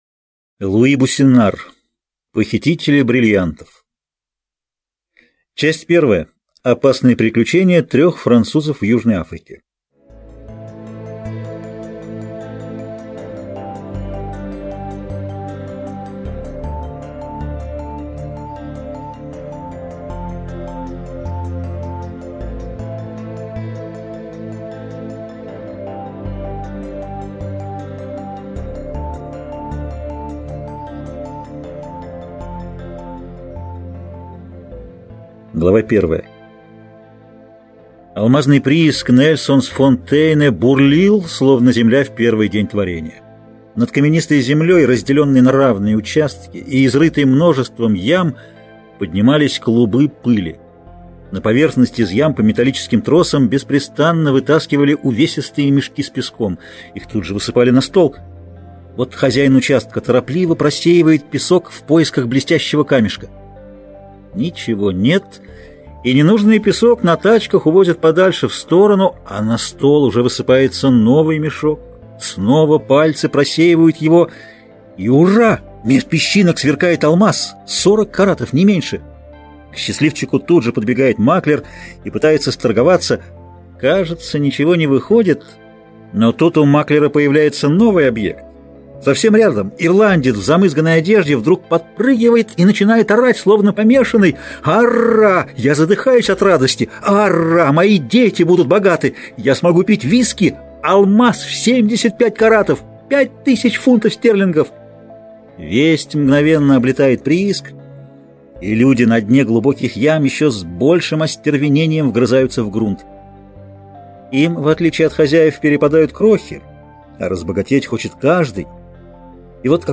Аудиокнига Похитители бриллиантов (сокращенный пересказ) | Библиотека аудиокниг